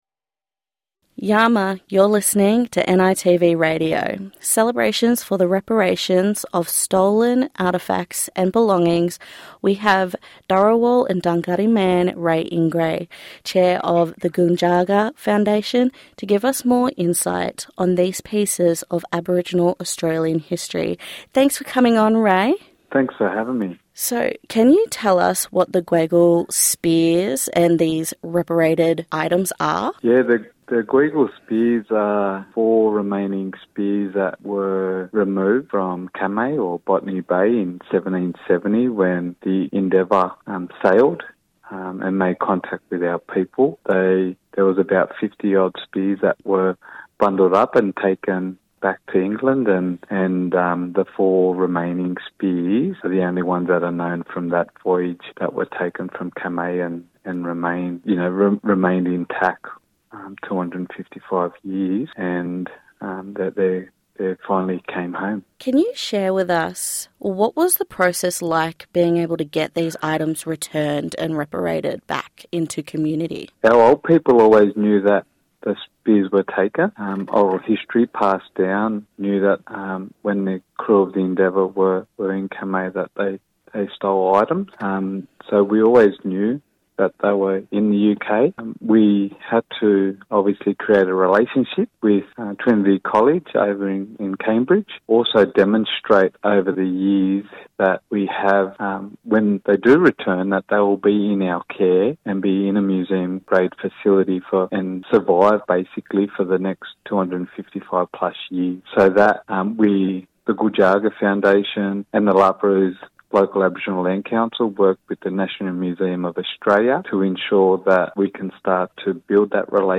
SBS NITV Radio